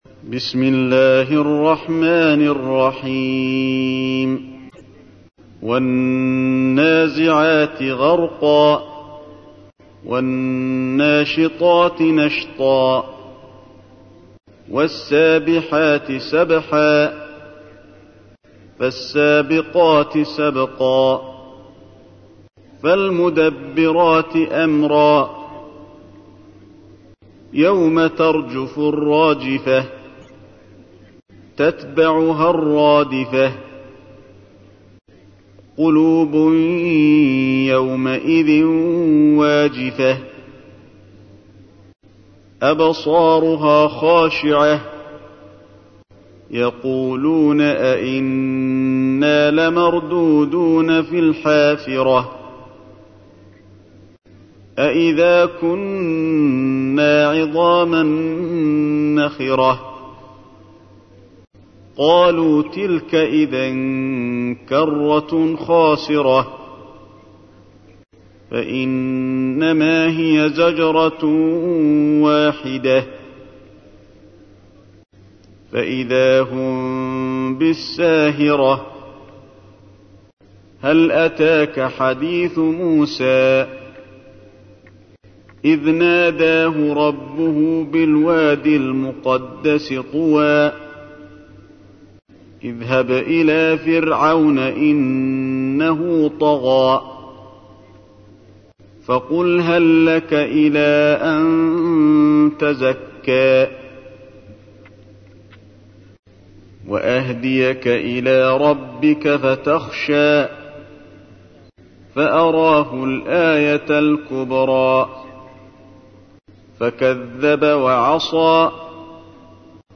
تحميل : 79. سورة النازعات / القارئ علي الحذيفي / القرآن الكريم / موقع يا حسين